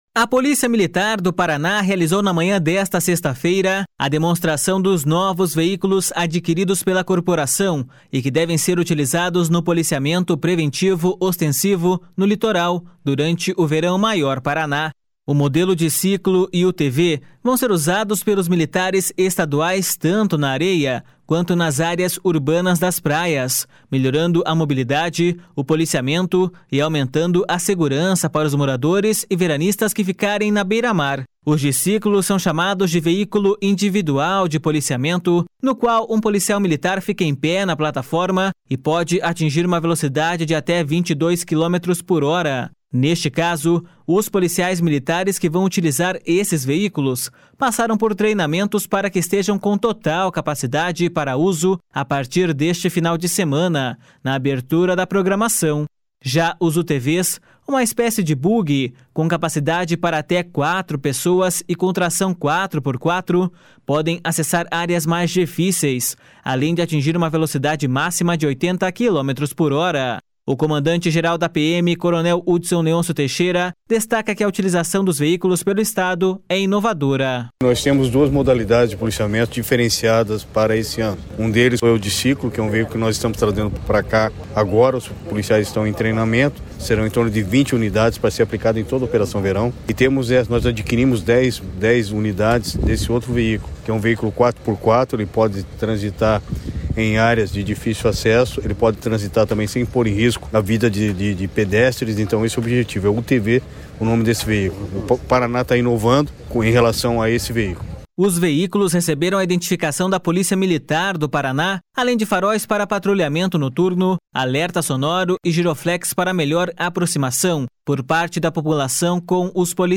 O comandante-geral da PMPR, coronel Hudson Leôncio Teixeira, destaca que a utilização dos veículos pelo Estado é inovadora.// SONORA HUDSON LEÔNCIO TEIXEIRA.//